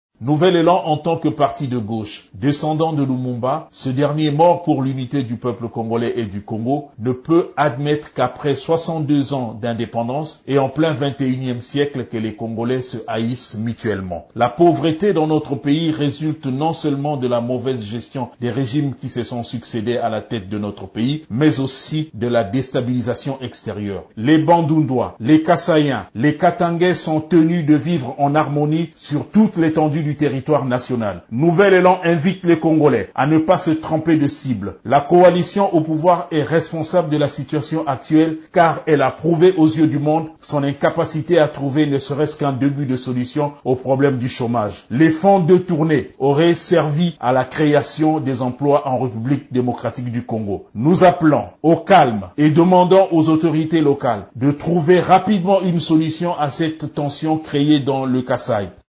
Pour sa part, le parti Nouvel Elan de l’ancien Premier ministre Adolphe Muzito appelle toutes les communautés à la cohabitation pacifique et au calme. Dans une déclaration faite lundi à Kinshasa